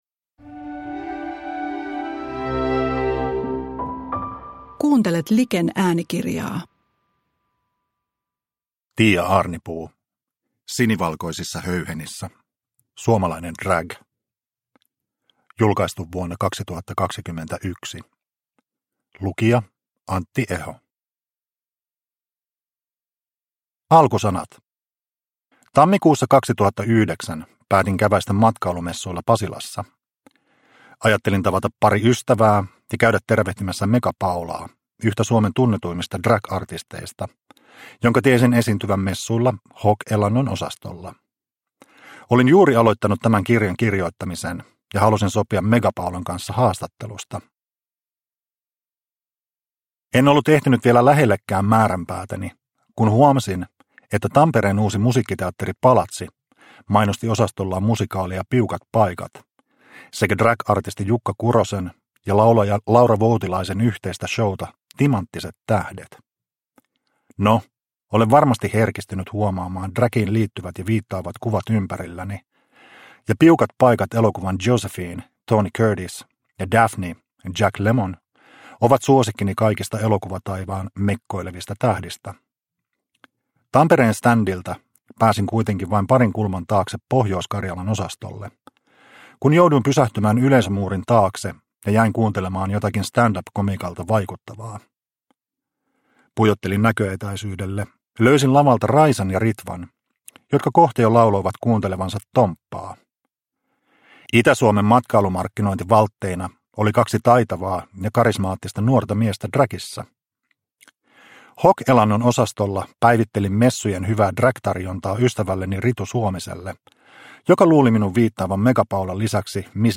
Sinivalkoisissa höyhenissä – Ljudbok – Laddas ner